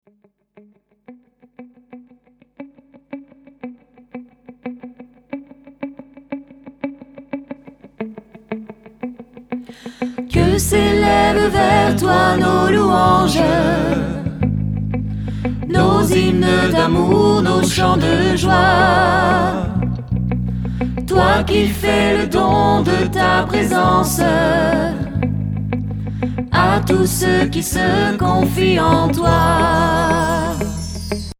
Critères liturgiques : Chant d'assemblée